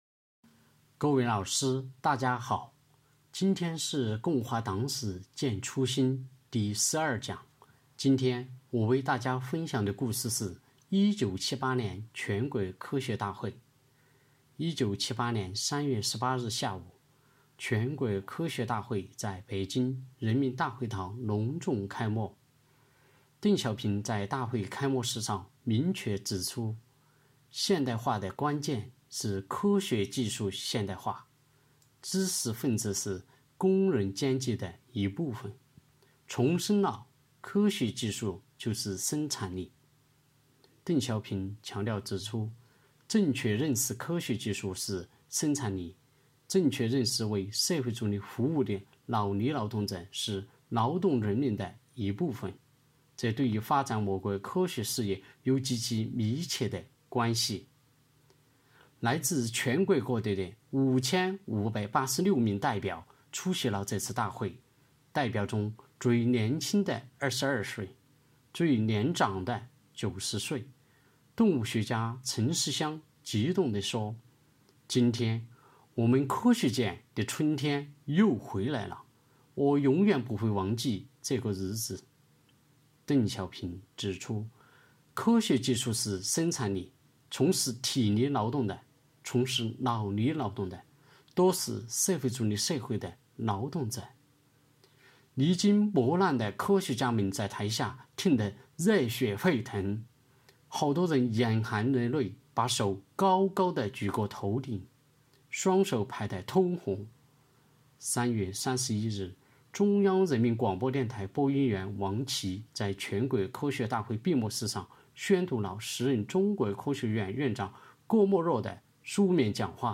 序章：为了加强组织建设，提高党员教育成效，落实党员“五个一”活动要求，科技处党支部从3月23日起开展了“共话党史践初心——党史人人讲”系列活动，党员每天讲一个党史故事，辅以相关学习内容的延伸阅读，以小见大，不断巩固“不忘初心 牢记使命”主题教育成果，以昂扬的精神面貌，庆祝中国共产党成立100周年！！！